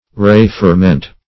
Meaning of re-ferment. re-ferment synonyms, pronunciation, spelling and more from Free Dictionary.
re-ferment.mp3